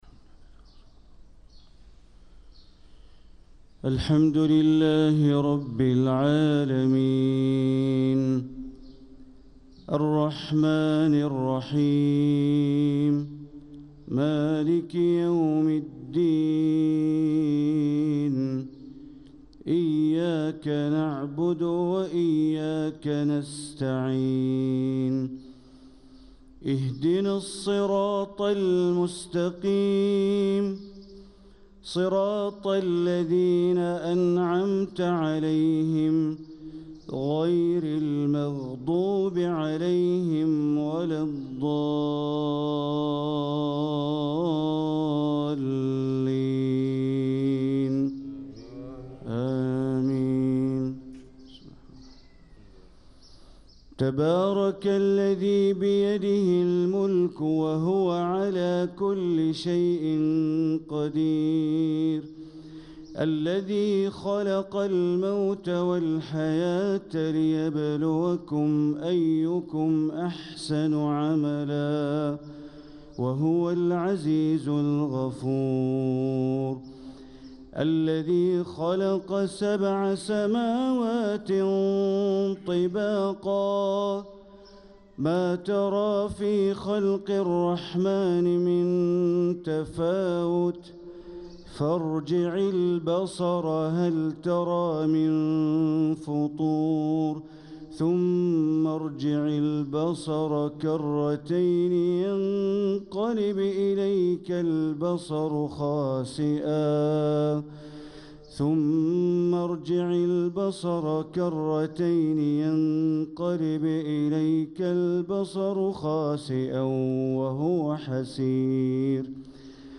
صلاة الفجر للقارئ بندر بليلة 13 رجب 1446 هـ